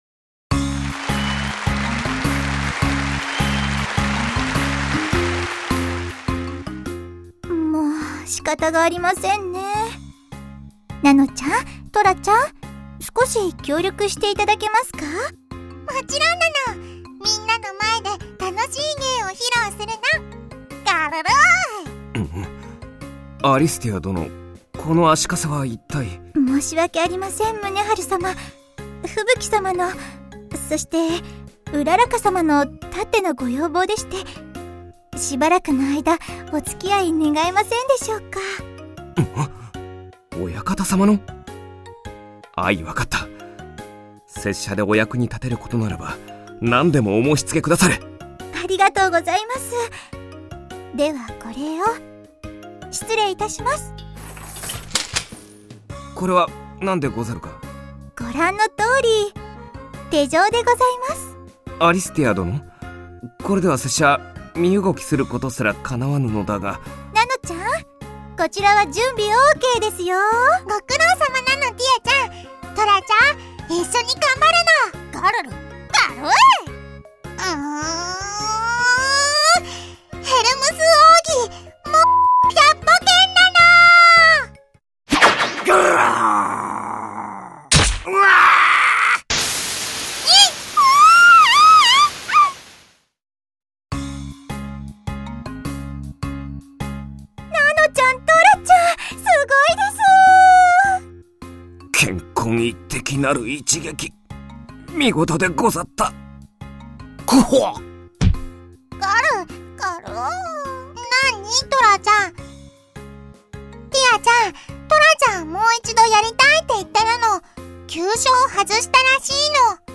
WEBドラマ 第5回を公開！！